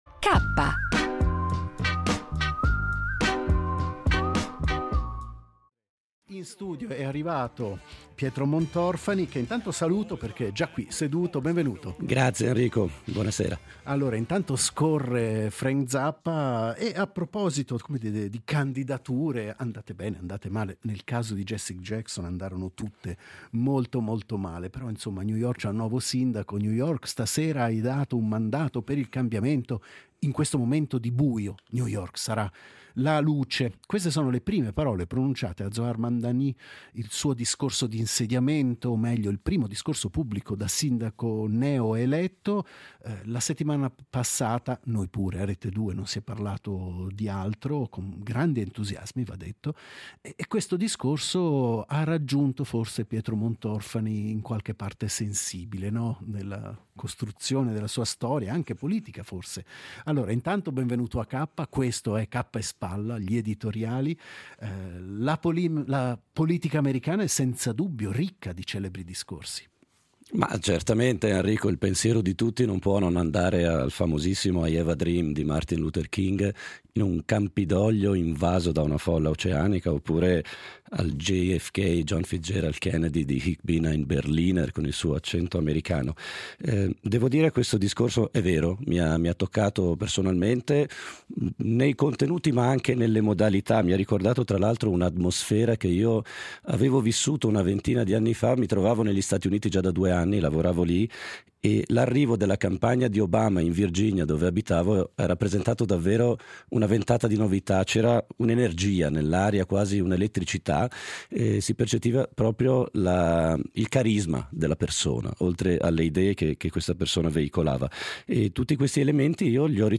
Editoriali tra cultura, attualità e sguardi sul costume